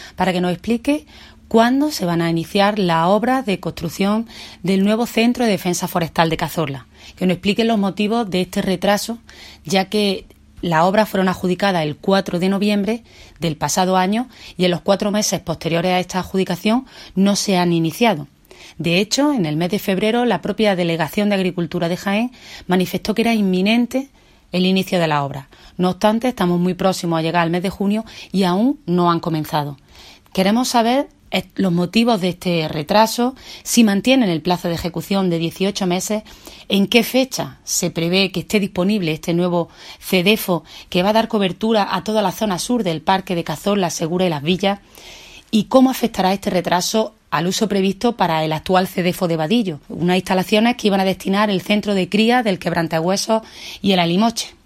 Cortes de sonido
Audio de Mercedes Gámez